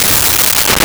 Woof
woof.wav